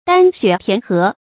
担雪填河 dān xuě tián hé
担雪填河发音